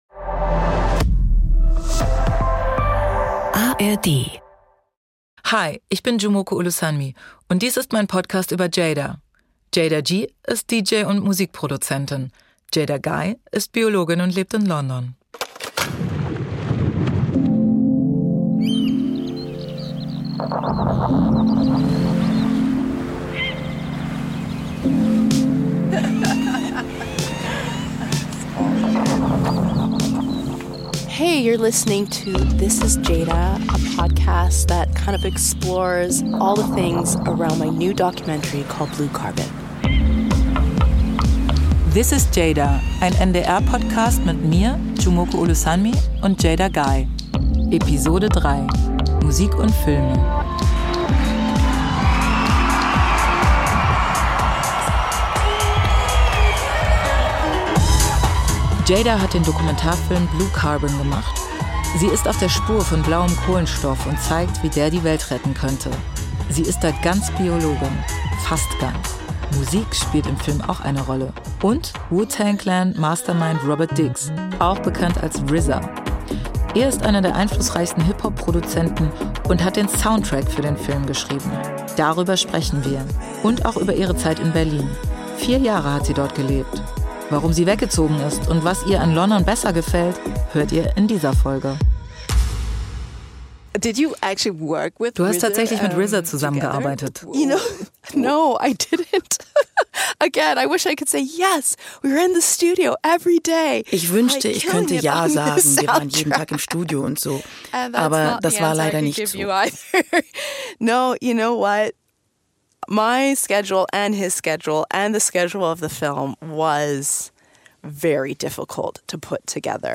Aufzeichnung des Interviews